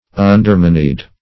Undermoneyed \Un`der*mon"eyed\